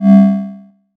forceField_004.ogg